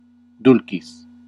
Ääntäminen
IPA : [dɪˈlaɪtfəɫ]